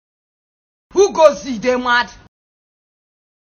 Who Goes You, You dey Mad - Comedy Sound Effect
Who-goes-you-you-dey-mad-Comedy-sound-effect.mp3